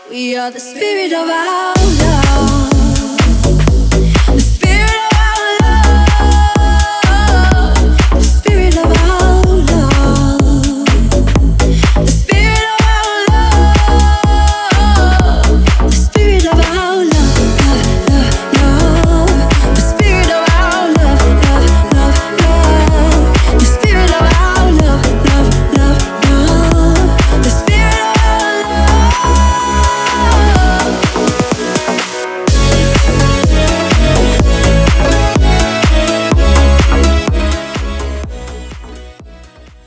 • Качество: 320, Stereo
поп
женский вокал